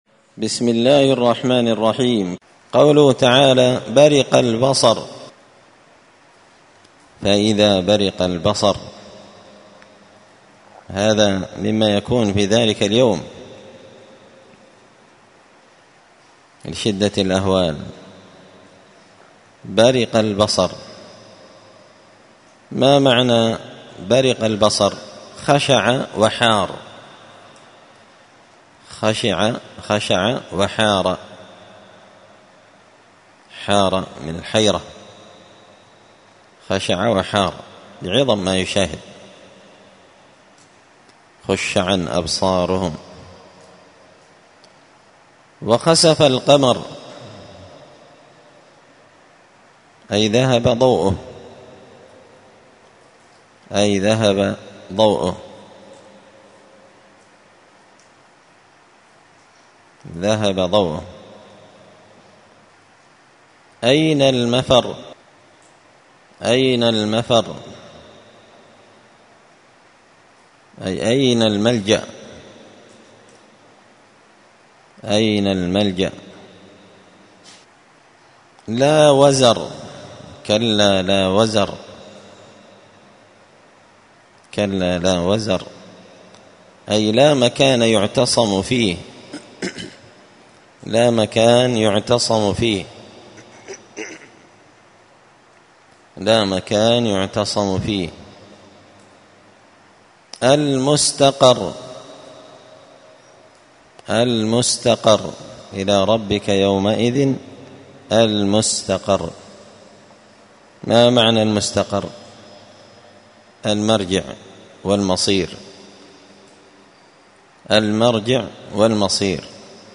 (جزء تبارك سورة القيامة الدرس 102)